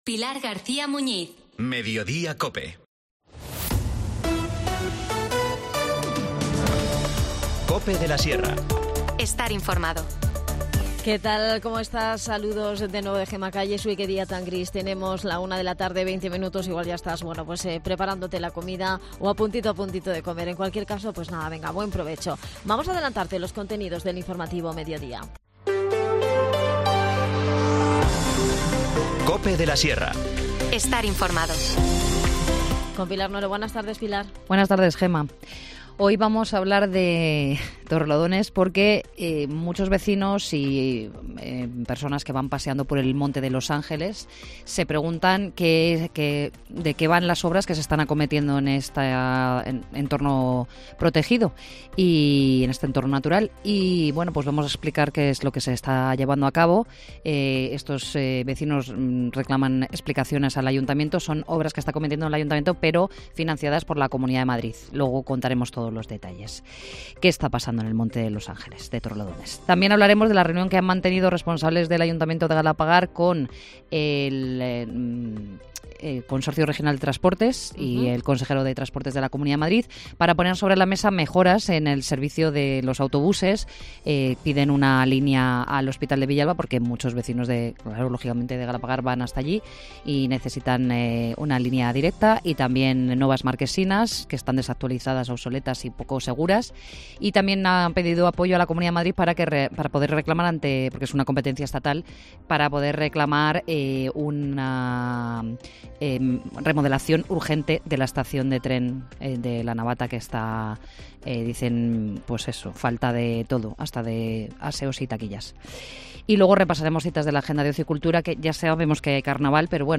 Nos cuenta más detalles sobre estas I Jornadas del Puchero, Estela Mosquera, concejal de Desarrollo Local, y algunos de los restaurantes participantes nos ponen los dientes largos con sus propuestas.